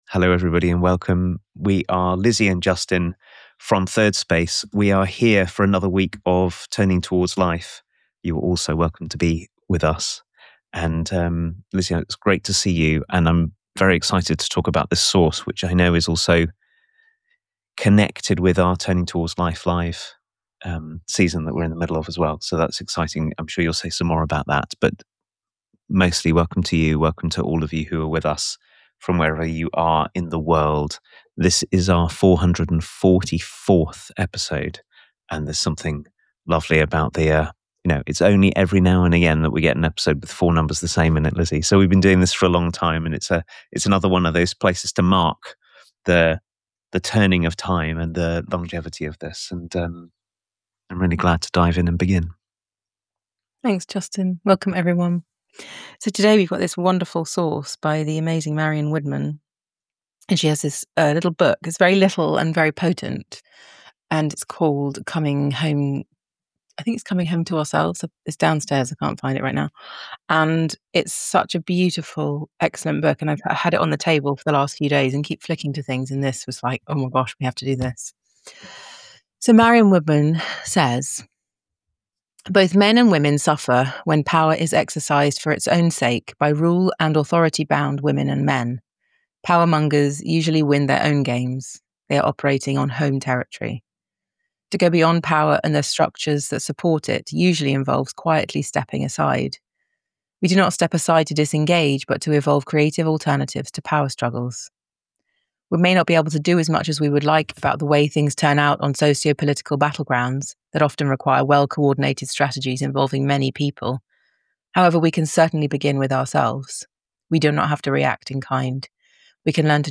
a weekly live 30 minute conversation